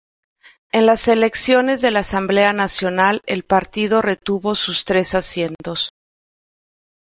Pronúnciase como (IPA) /aˈsjentos/